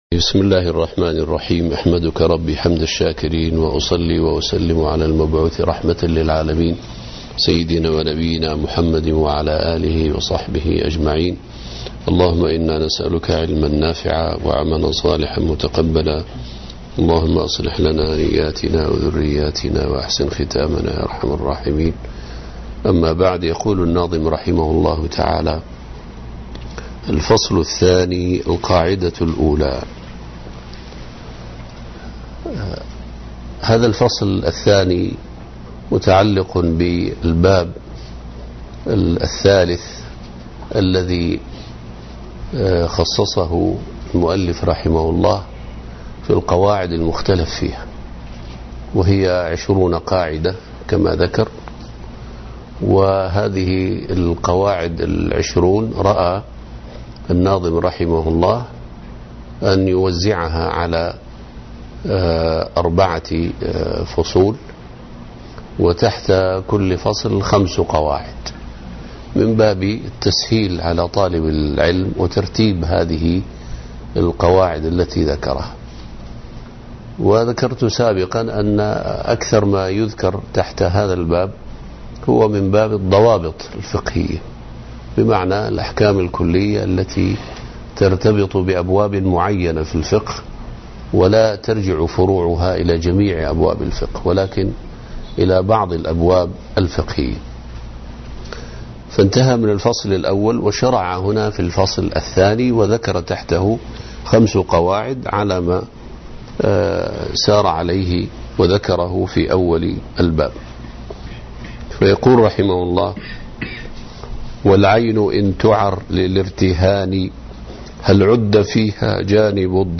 التسجيل الصوتي للدرس